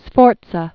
(sfôrtsə, sfôrtsä)